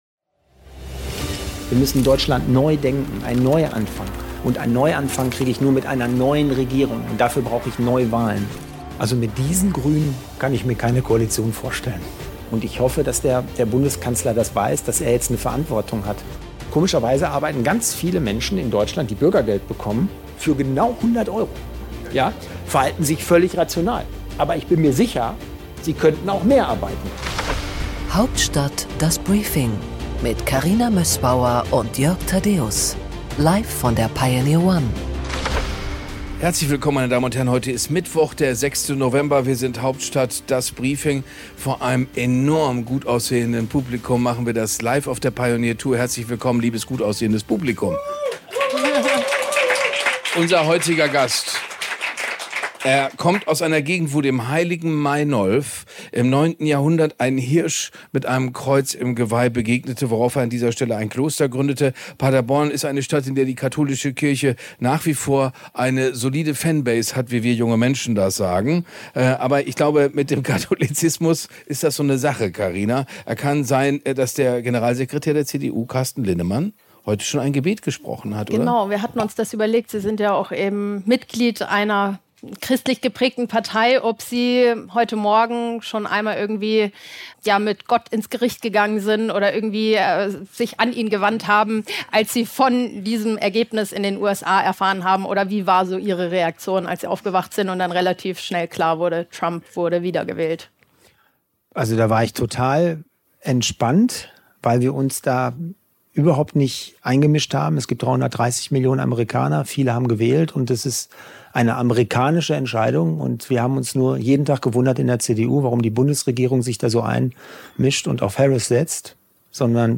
Hauptstadt - Das Briefing LIVE